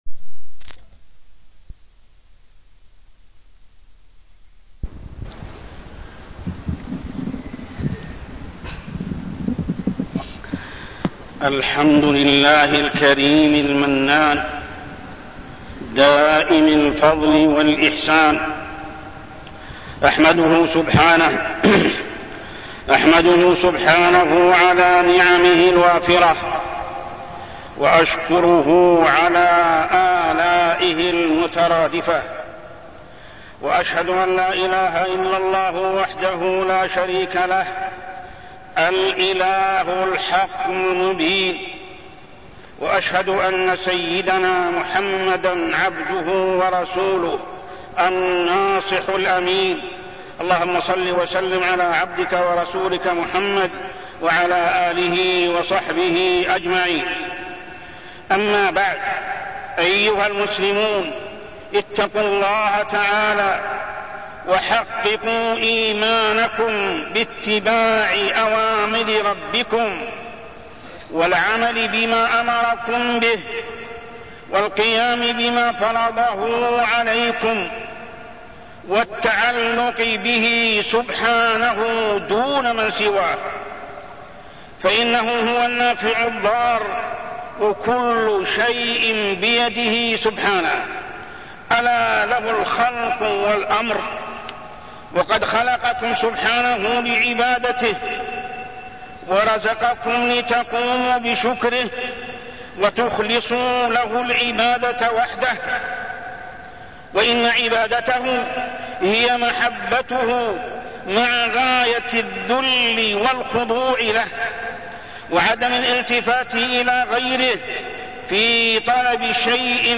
تاريخ النشر ٧ رمضان ١٤١٩ هـ المكان: المسجد الحرام الشيخ: محمد بن عبد الله السبيل محمد بن عبد الله السبيل فريضة الزكاة والحذر من منعها